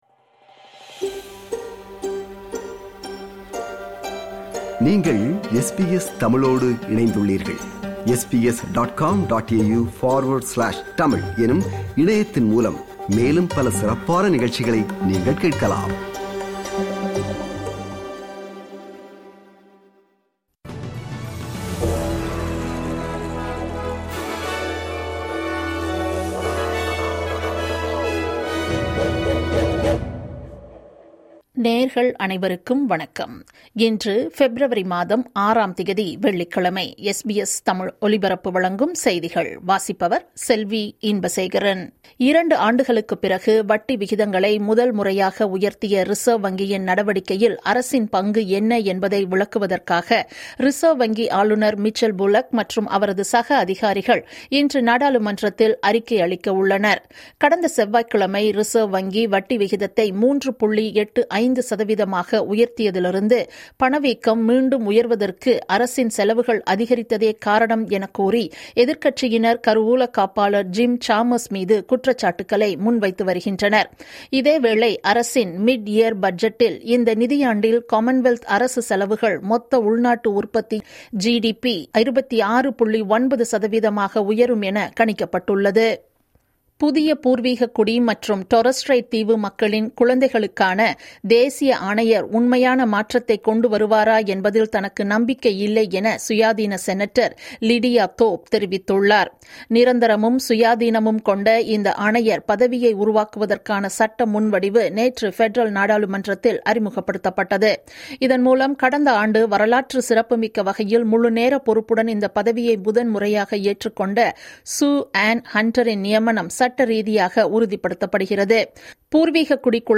SBS தமிழ் ஒலிபரப்பின் இன்றைய (வெள்ளிக்கிழமை 06/02/2026) செய்திகள்.